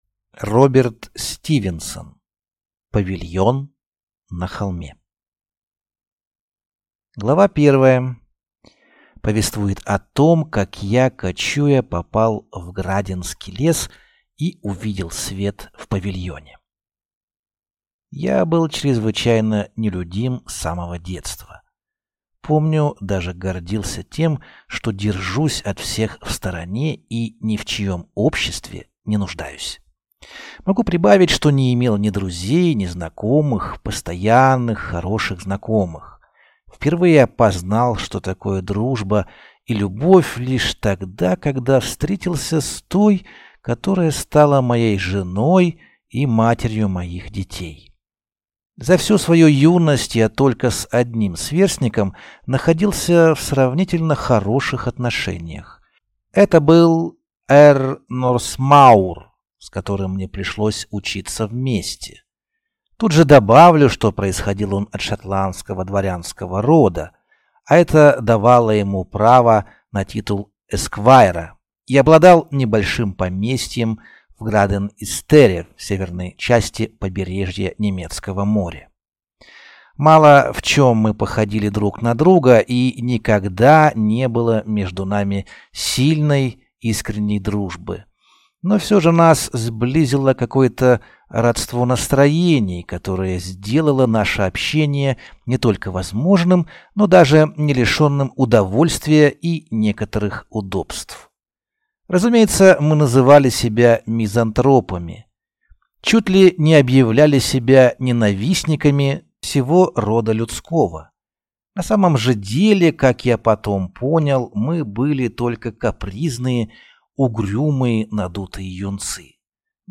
Аудиокнига Павильон на холме | Библиотека аудиокниг